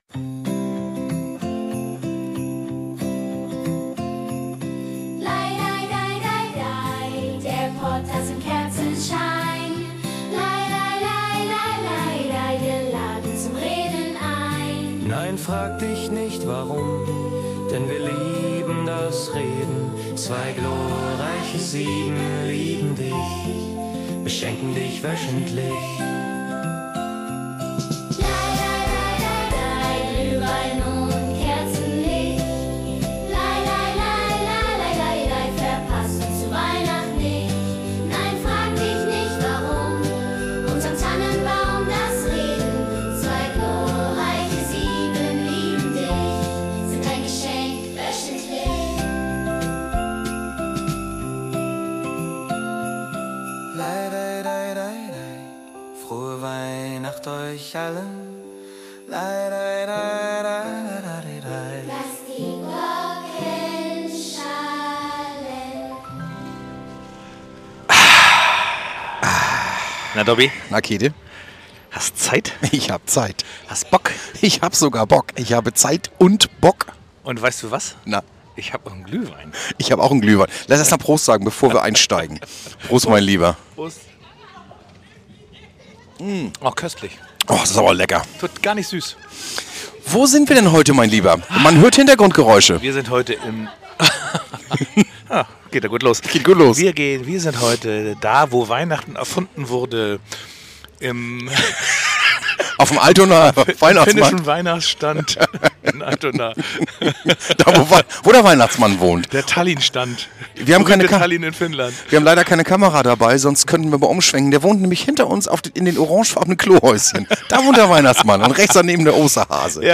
Vom Ottenser Weihnachtszauber direkt in eure Ohren. Der Rückblick auf die Folgen des Jahres 2025.